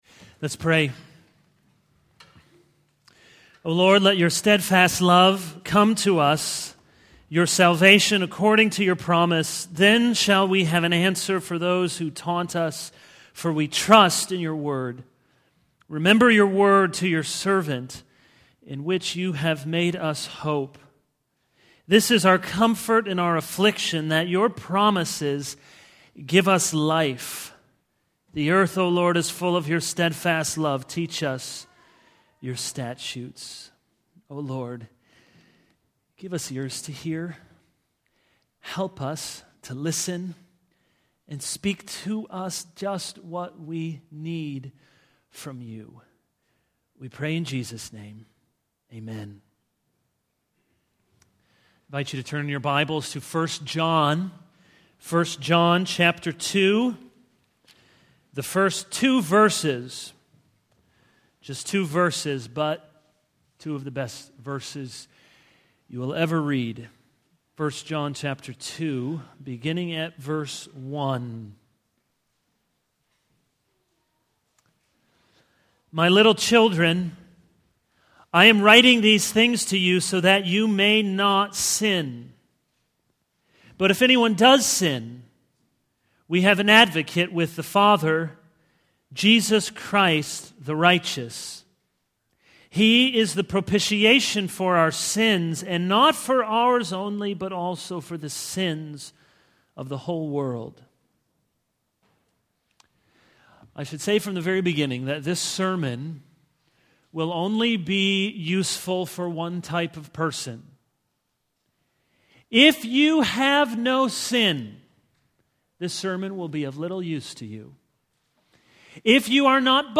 This is a sermon on 1 John 2:1-2.